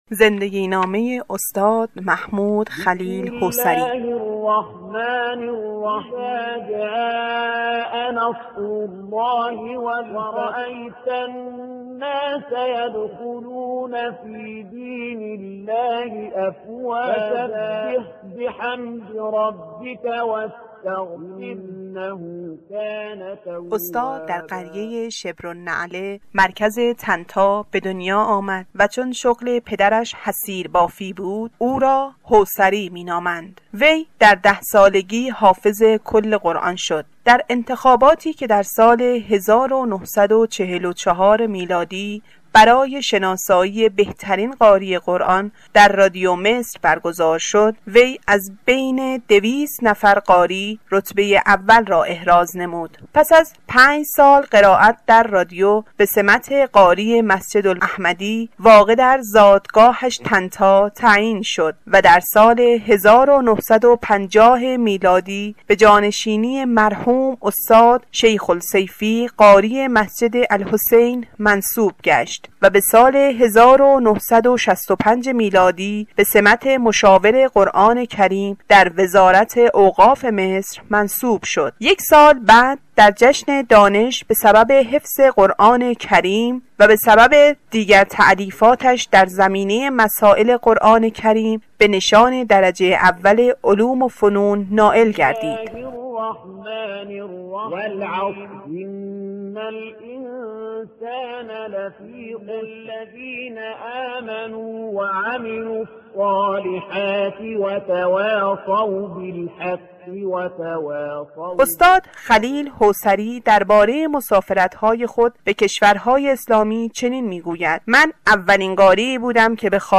اولین ترتیل
قاری